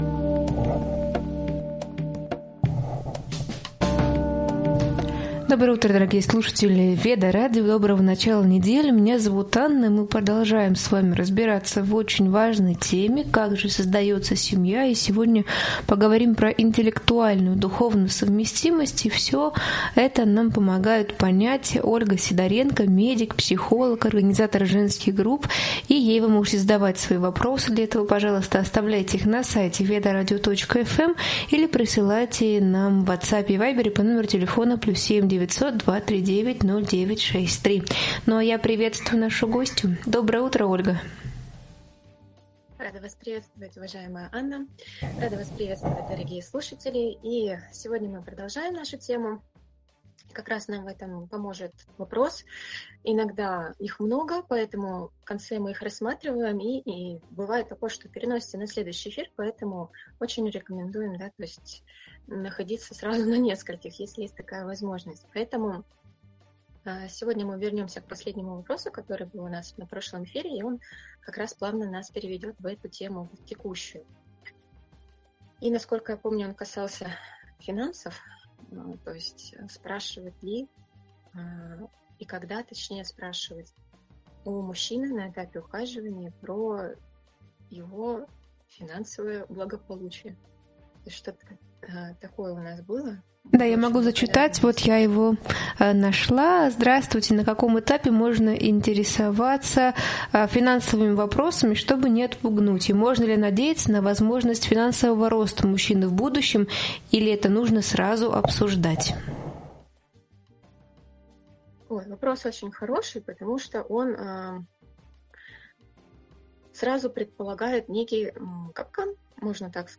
Эфир посвящён интеллектуальной и духовной совместимости как основе прочной семьи. Обсуждаются различия мужской и женской природы, роль разума, эмоций и ценностей при выборе партнёра, вопросы финансов, привязанности и уважения.